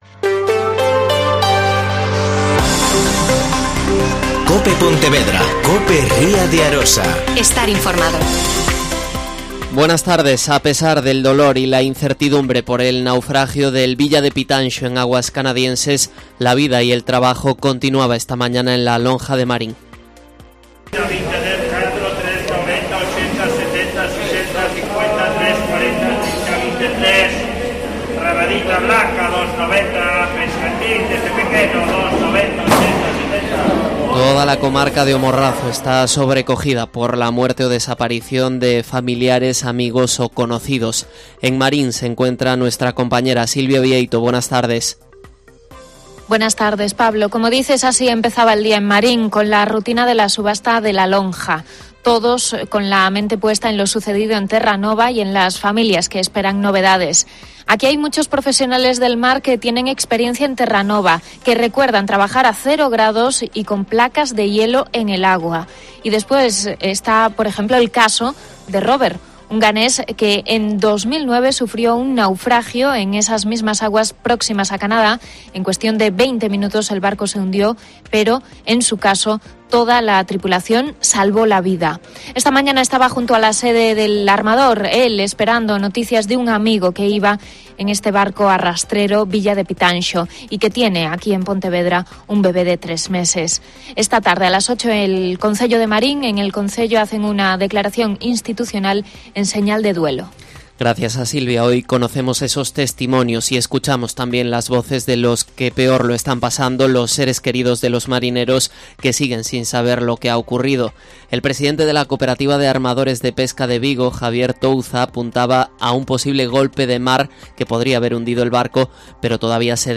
Mediodía COPE Pontevedra y COPE Ría de Arosa (Informativo 14:20h.)